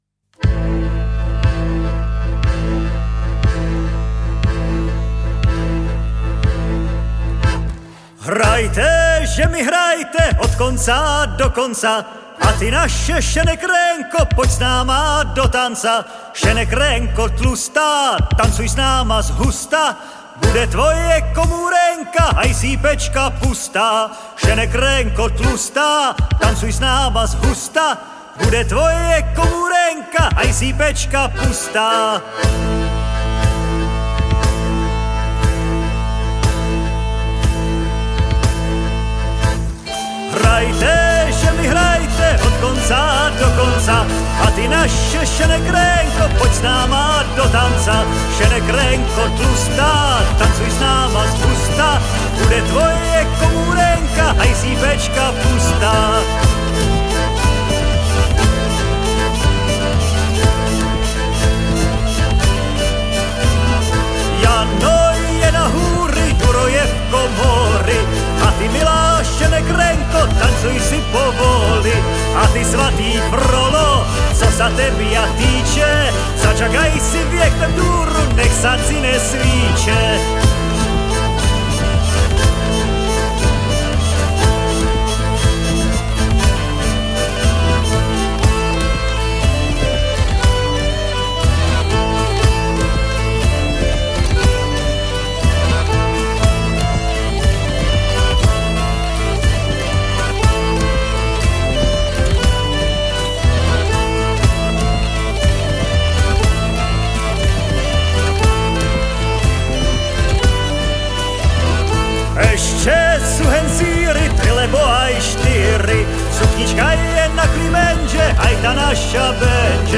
Žánr: World music/Ethno/Folk
bal folk nuevo. tramiditional dance music.
polka